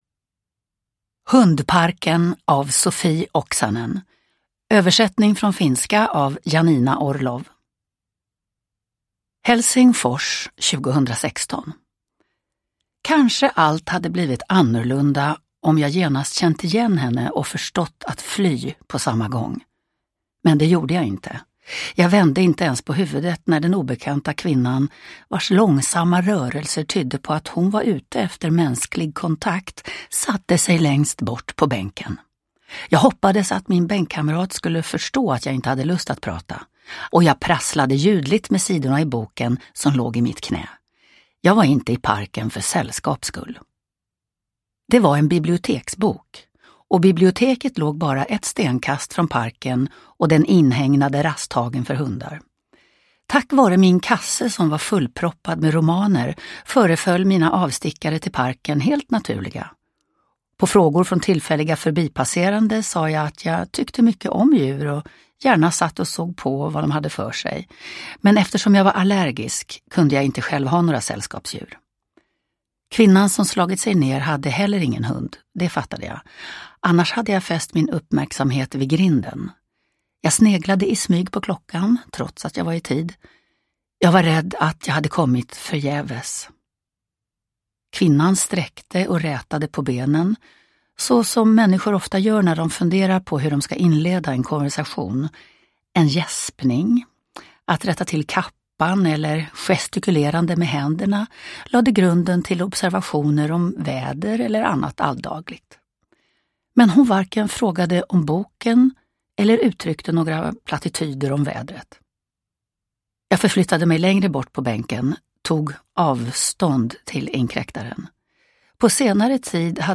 Uppläsare: Lena Endre